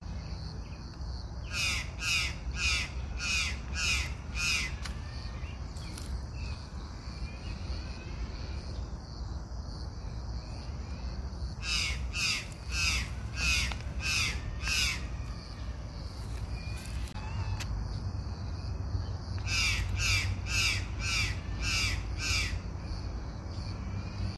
Curl-crested Jay (Cyanocorax cristatellus)
Detailed location: Parque de la ciudad
Condition: Wild
Certainty: Photographed, Recorded vocal
Urraca-de-cresta-rizada_1_1.mp3